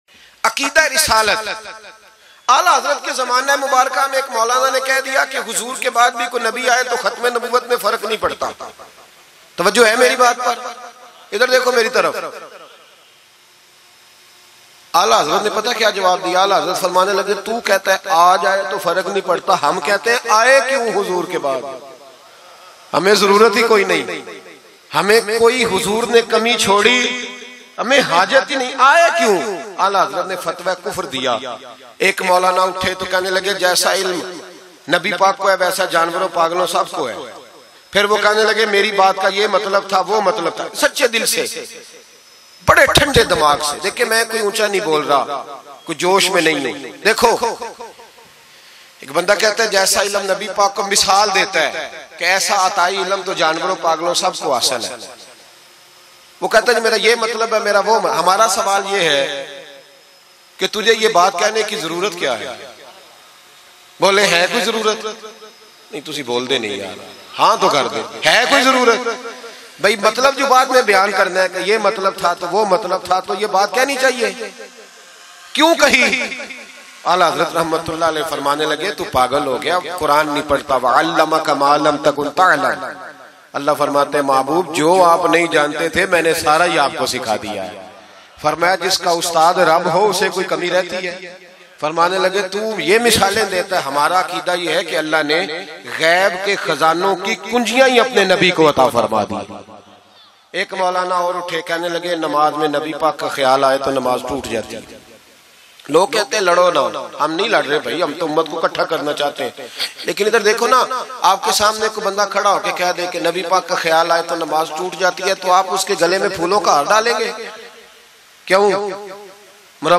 Ishq e Nabi Izat e Nabi Hamari Jaan Bayan